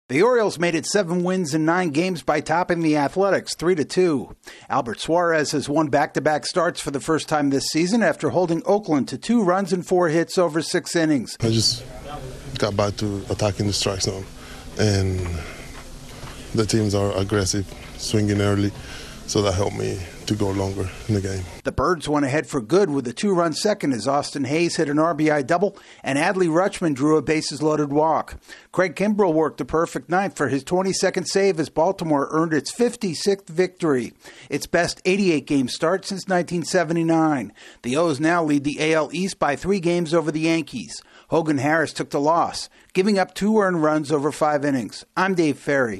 The Orioles expand their division lead by nipping the A's. AP correspondent